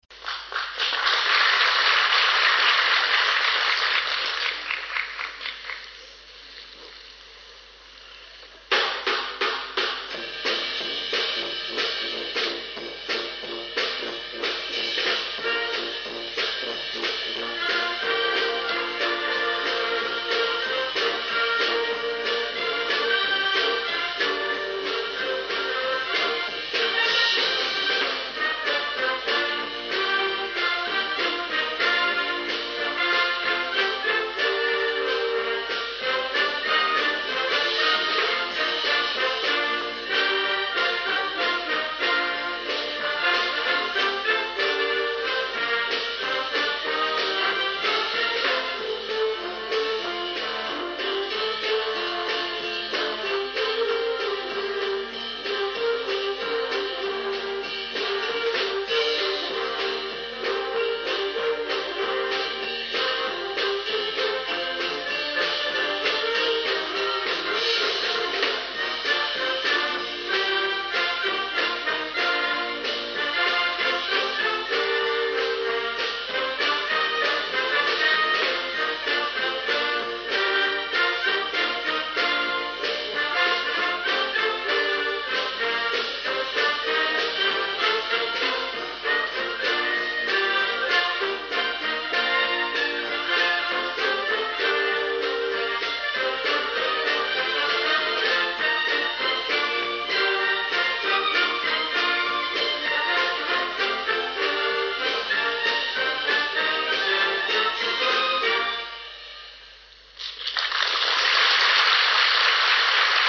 合奏を録音したMP3ファイルです。
2000年春季リーグ戦のチア曲。
録音は2000年の富岡六旗です。
演奏はこの年の当番校、明治大学です。